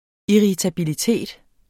Udtale [ iɐ̯itabiliˈteˀd ]